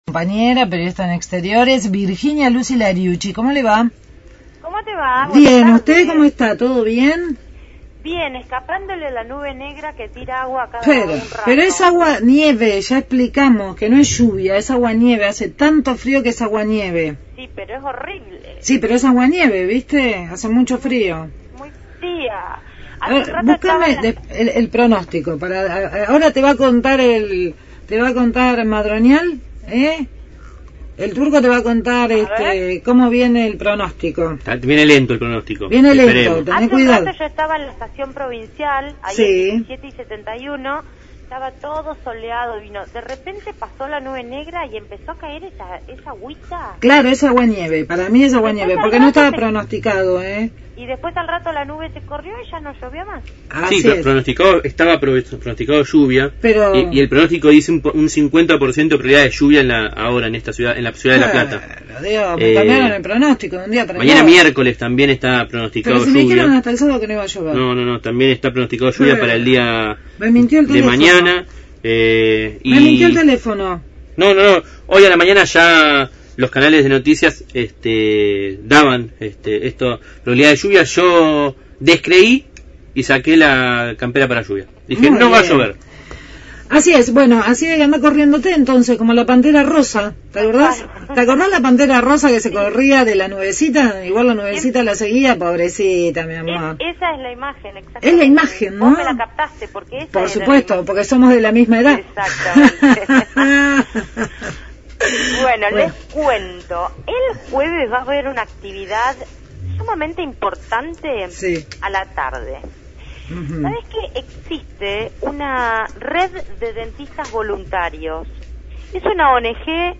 Móvil/ Controles odontológicos gratuitos a niños en La Plata – Radio Universidad